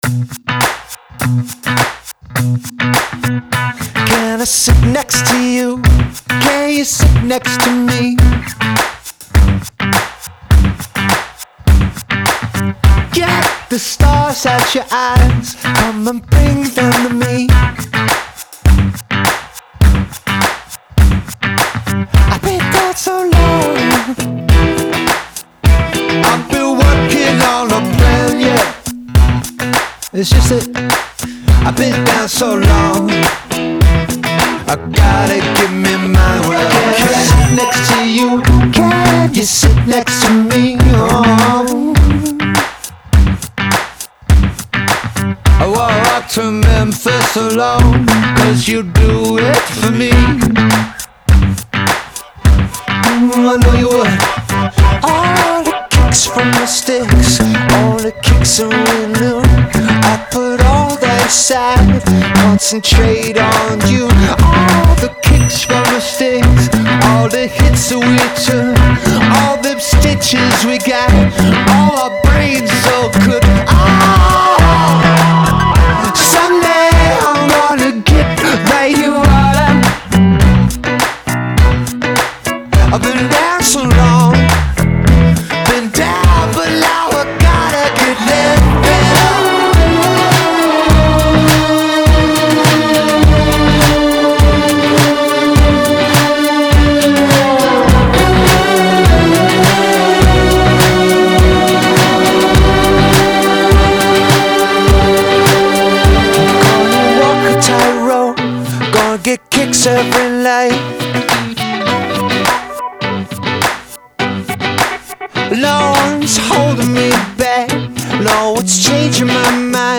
With their brisk energy and hook-heavy sound